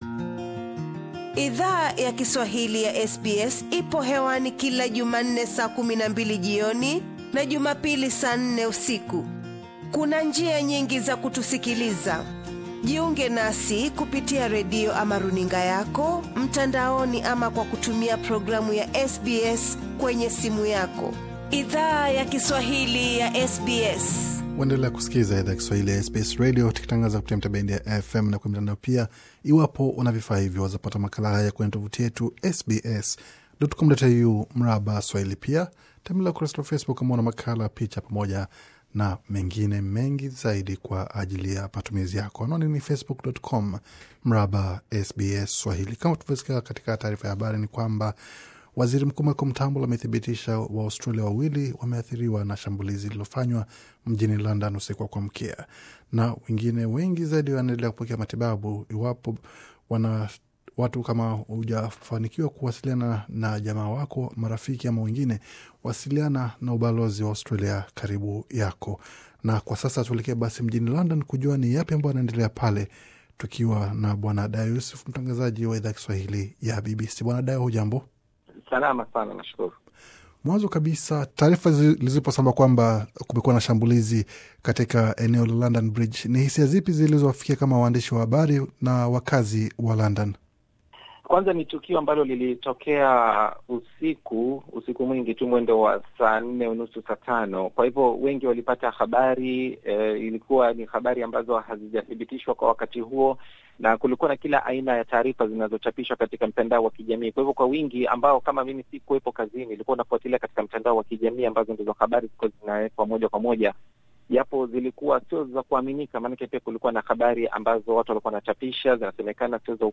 Masaa machache baada ya shambulizi lililo sababisha vifo vya watu 10 na wengine zaidi kujeruhiwa, SBS Swahili ilizungumza na mwandishi wa habari anaye ishi London, kuhusu hali ya wakazi na mji wa London baada ya shambulizi hilo.